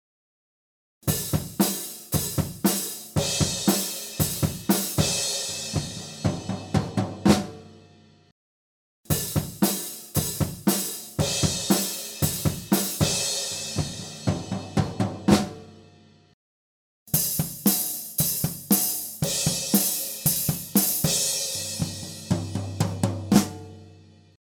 Διερεύνηση στερεοφωνικών τεχνικών ηχογράφησης και μίξης κρουστού οργάνου (drums) μέσω διαφορετικών στερεοφωνικών τεχνικών μικροφώνων.